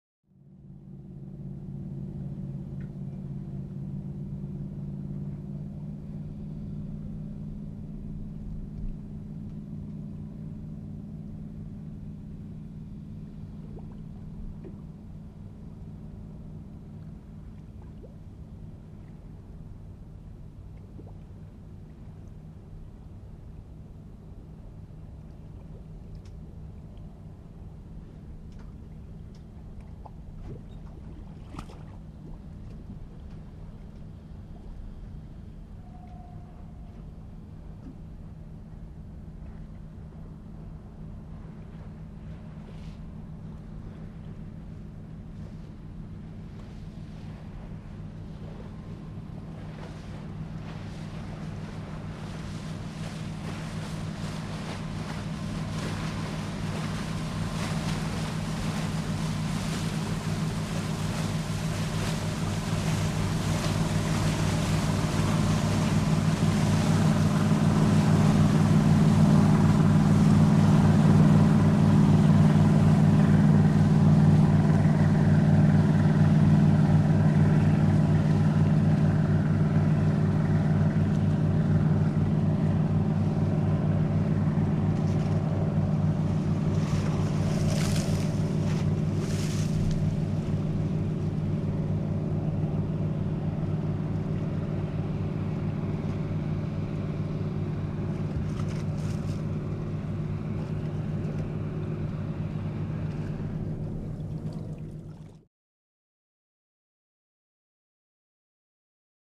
Power Yacht 6; Away, Then Turn Back, And By. Very Long In And Away, With Wake Splashes On Dock. Shorter Approach And More Water Sounds On Dock Than FX 93.